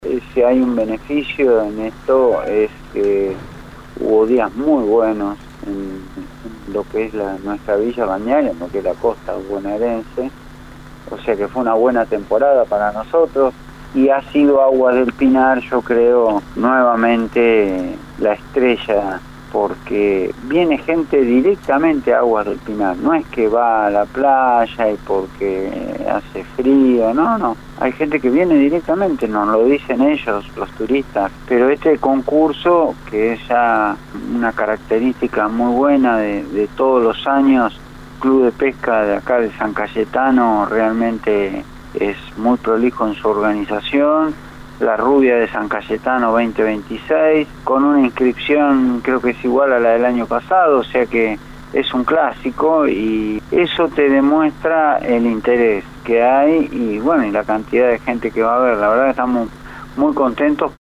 En una conversación con LU24, el intendente de San Cayetano, Miguel Ángel Gargaglione, realizó un balance exhaustivo de la actualidad del distrito, donde el éxito turístico convive con la preocupación climática que afecta al sector agropecuario.